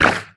assets / minecraft / sounds / mob / squid / hurt2.ogg
hurt2.ogg